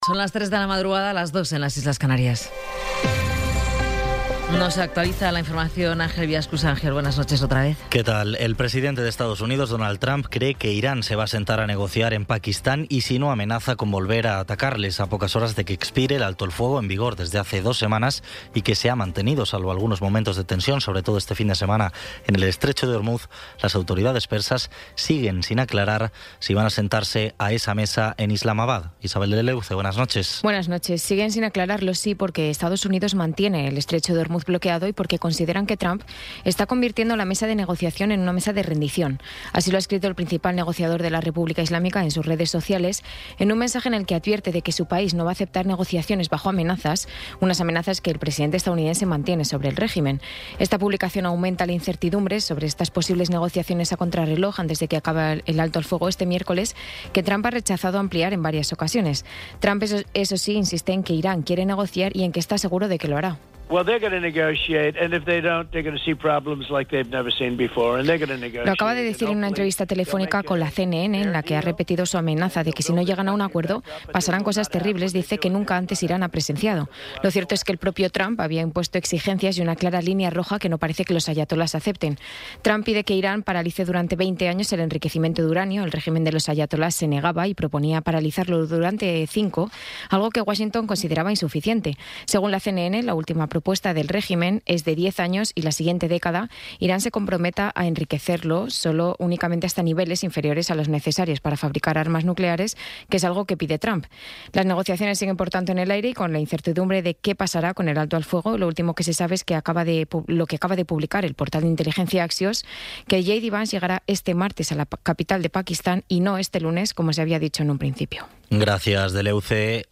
Resumen informativo con las noticias más destacadas del 21 de abril de 2026 a las tres de la mañana.